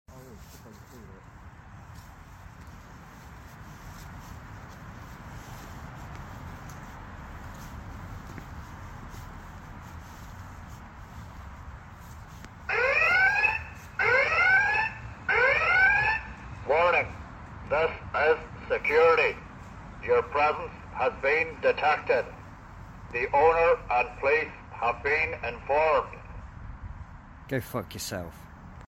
Setting off the Armadillo security sound effects free download